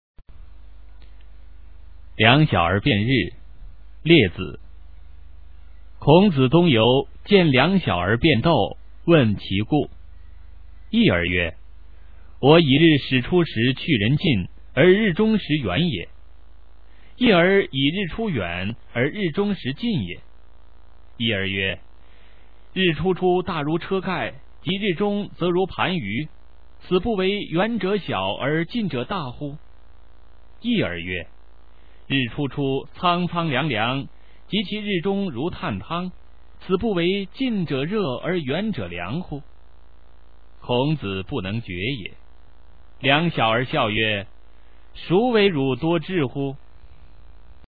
《两小儿辩日》原文、译文、注释（含Mp3朗读）　/ 佚名